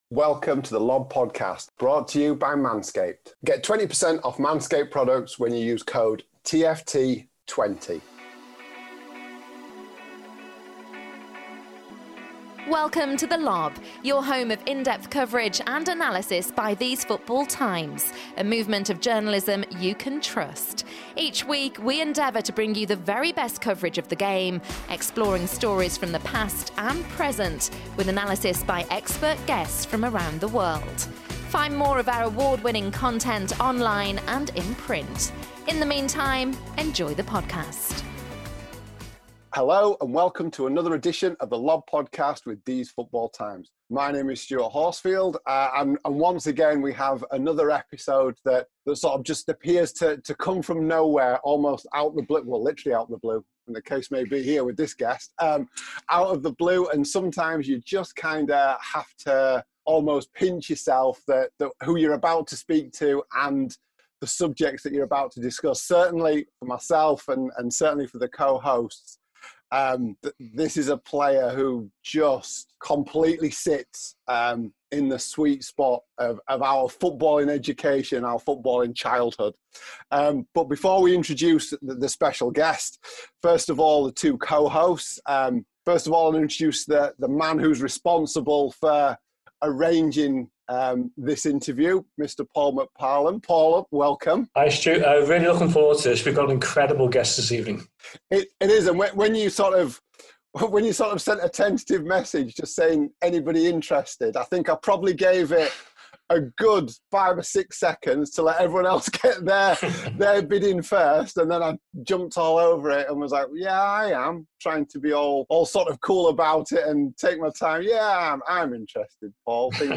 An interview with Manchester City icon Paul Power (part one)